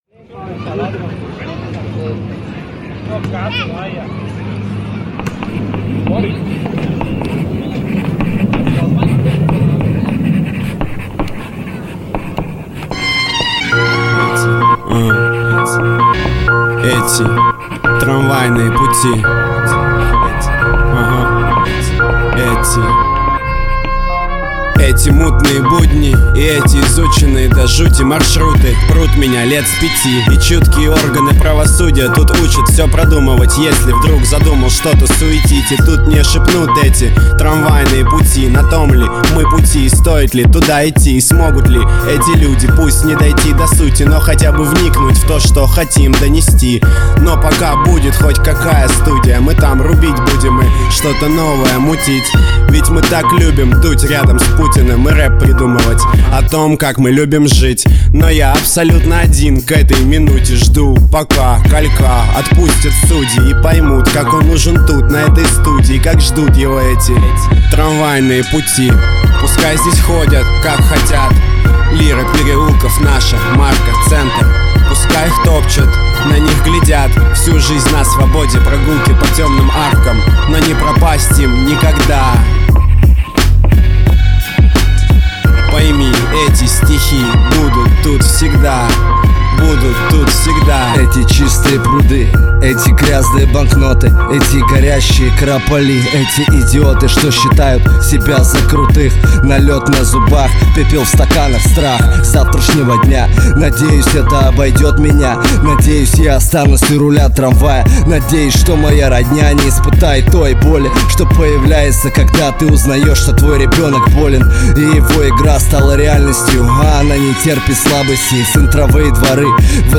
Главная » Русский реп, хип-хоп